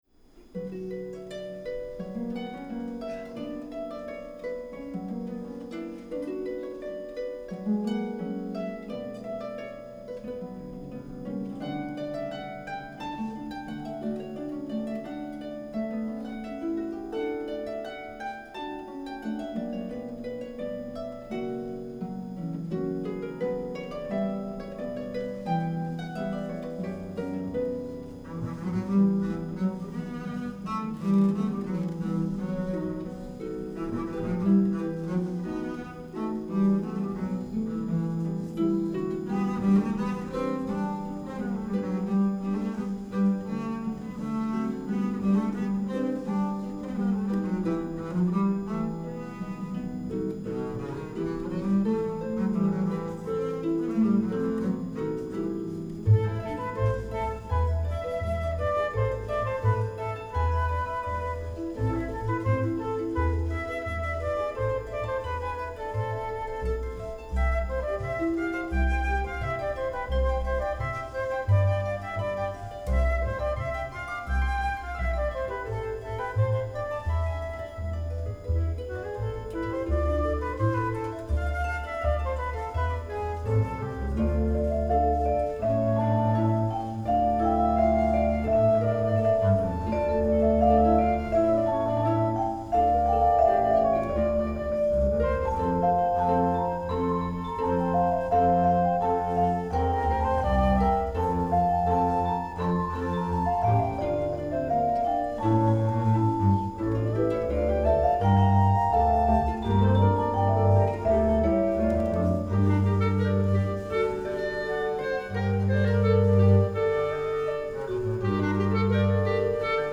La jornada del lunes estuvo dedicada a la música de escena